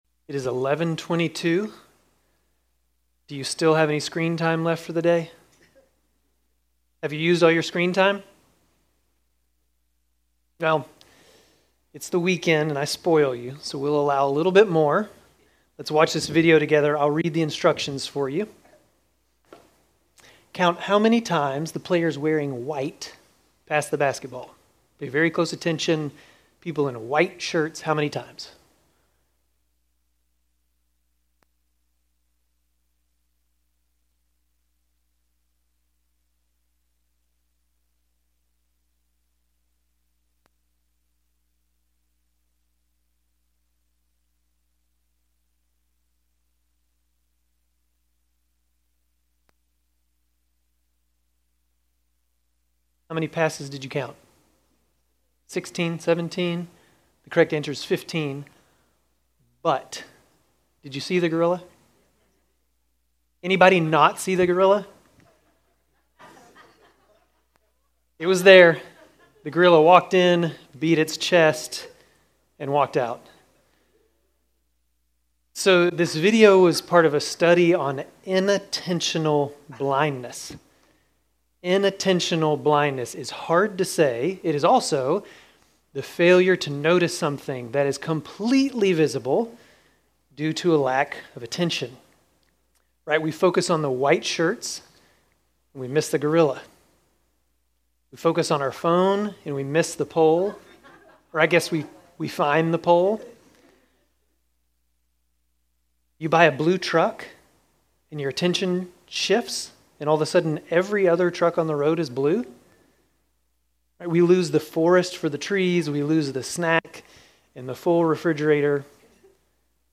Grace Community Church Dover Campus Sermons 10_5 Dover Campus Oct 06 2025 | 00:32:43 1x 00:00 / 00:32:43 Subscribe Share RSS Feed Share Link Embed